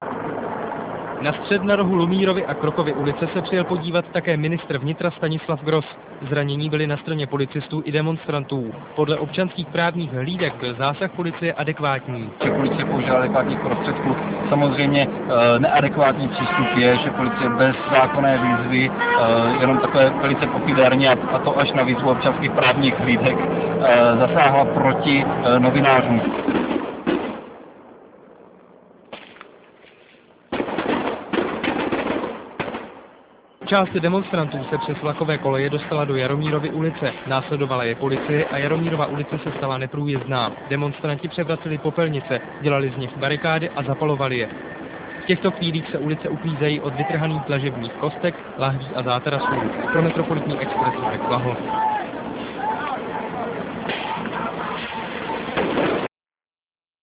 Nejprve jeden z televizních klipů TV3, z pořadu